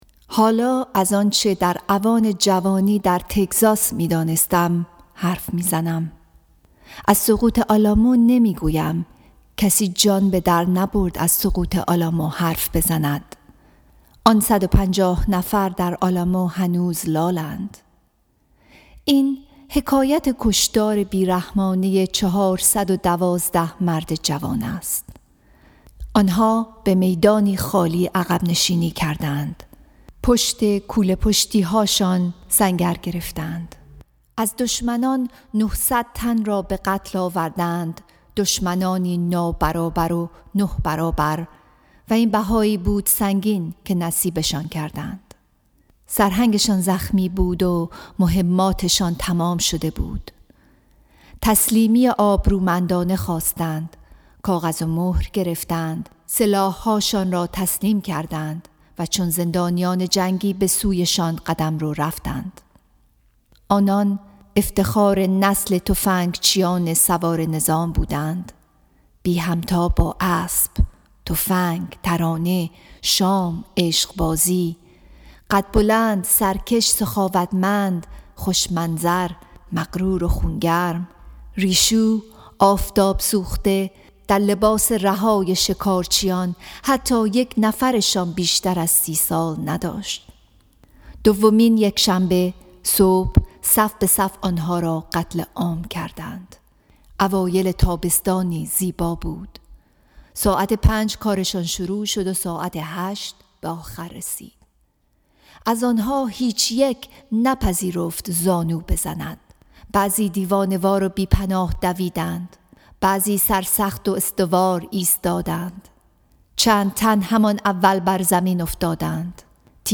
Song of Myself, Section 34 —poem read